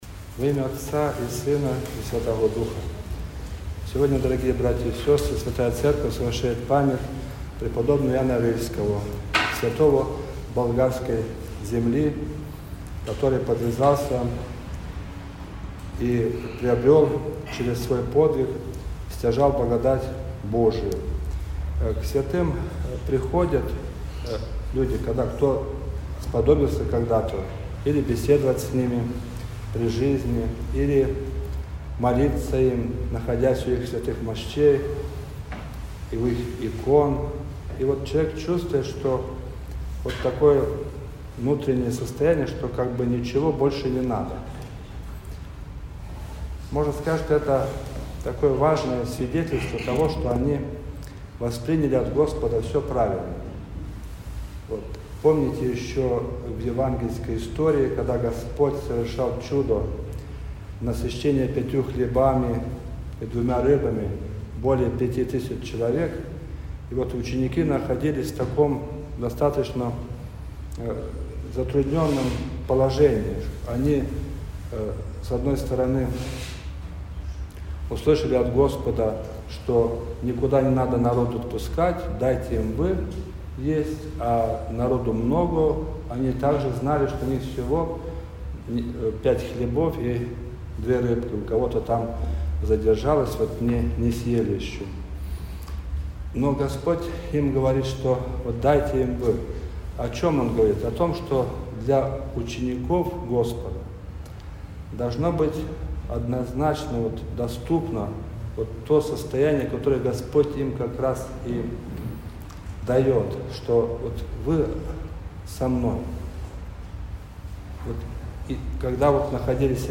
Проповедь.mp3